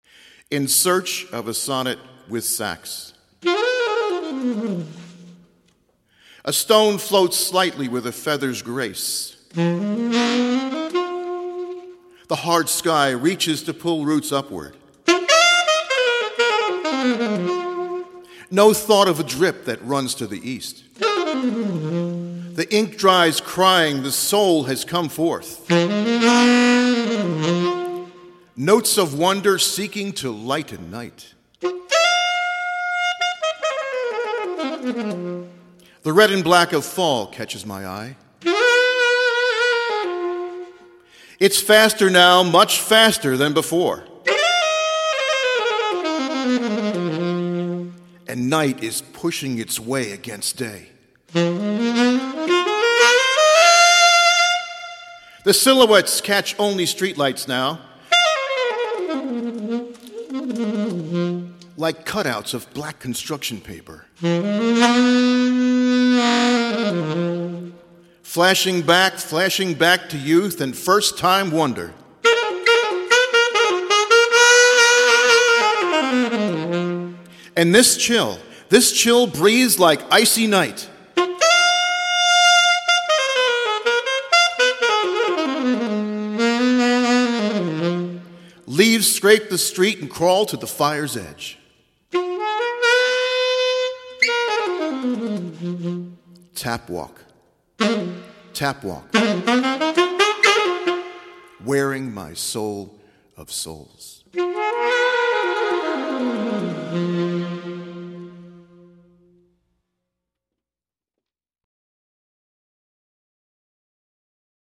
click here for a reading by the poet